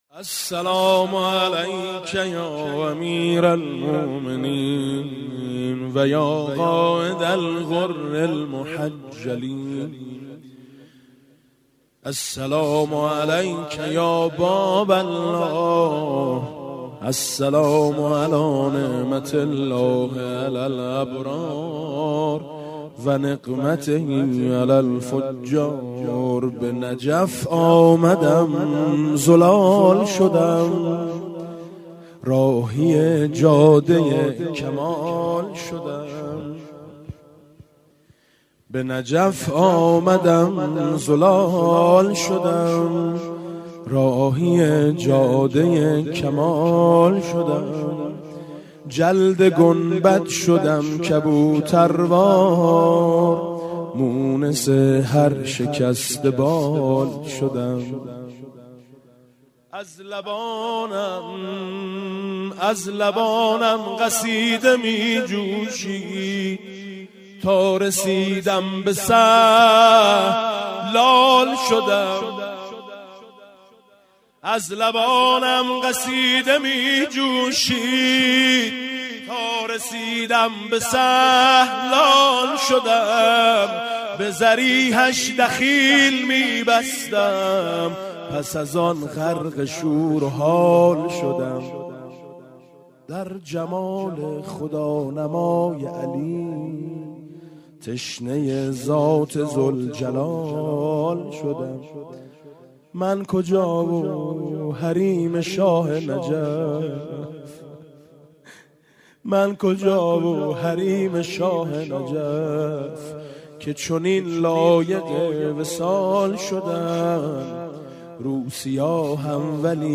استقبال از ماه رمضان 96 - مسجد بهشتی - مدح امیرالمؤمنین علیه السلام
مدح حاج میثم مطیعی